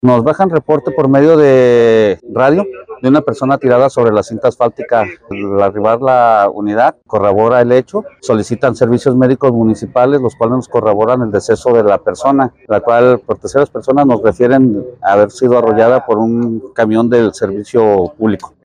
Habla de este hecho.